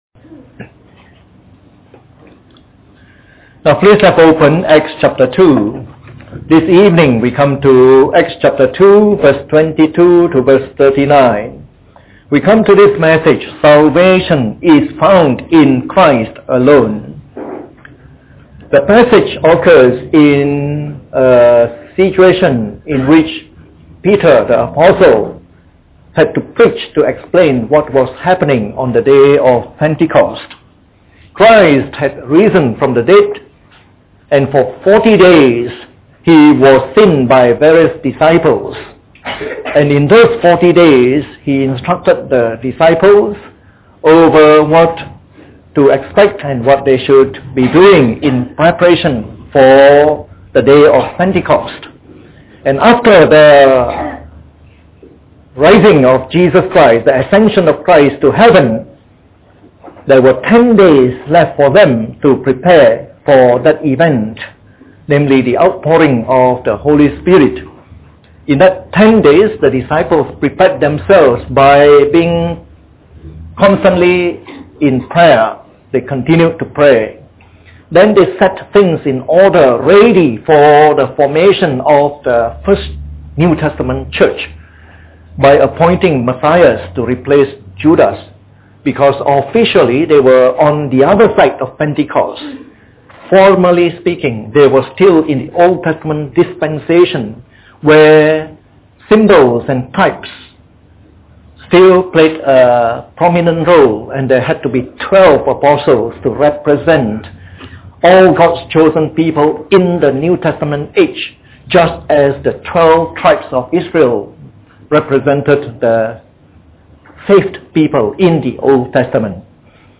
Part of our new series on “The Acts of the Apostles” delivered in the Evening Service.
We apologize for the poor audio quality of this mp3. We were having technical problems and the use of a backup computer was not sufficient to alleviate them entirely.